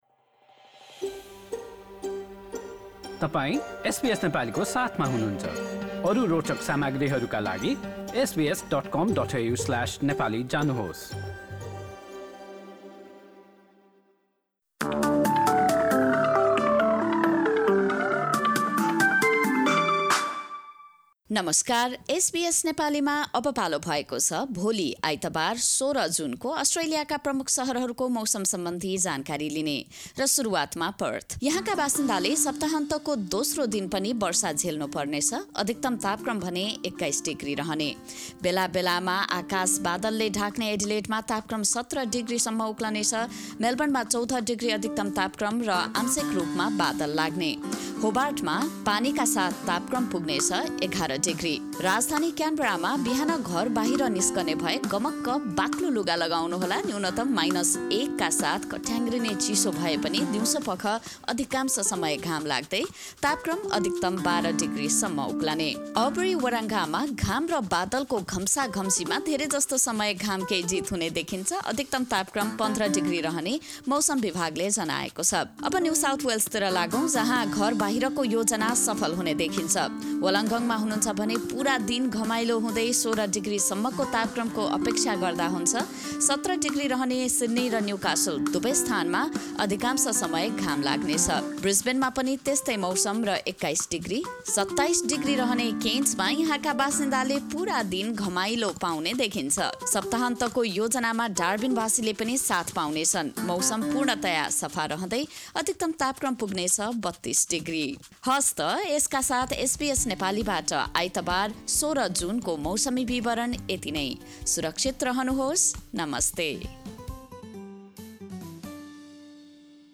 A quick weather update for major Australian cities for Sunday, 16 June 2024 in Nepali language.